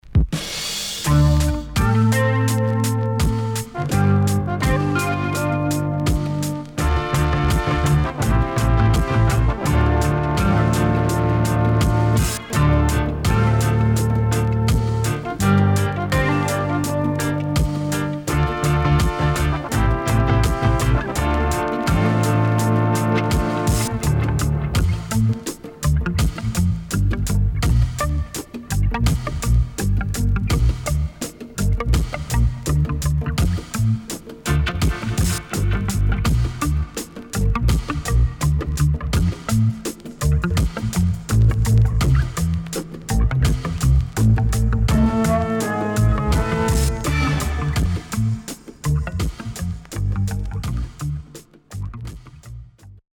CONDITION SIDE A:VG+
SIDE A:少しチリノイズ入ります。